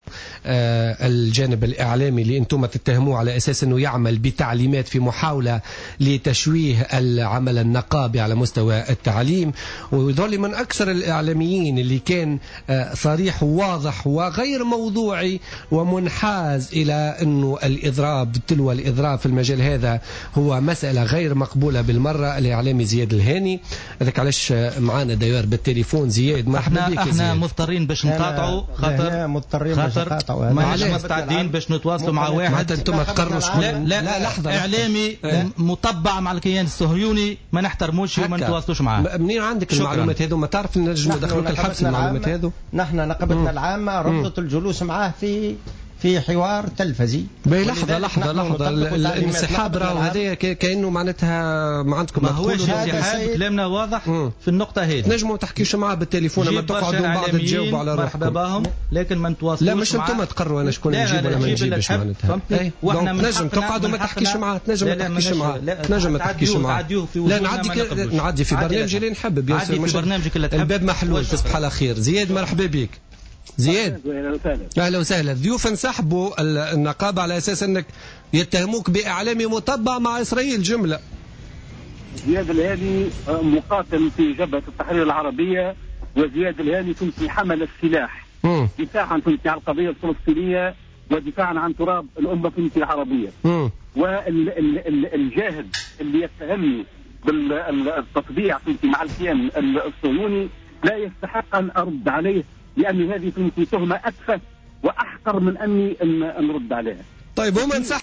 لحظة انسحاب ضيفي بوليتيكا